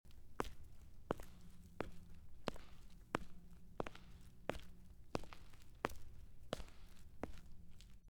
Download Walking sound effect for free.
Walking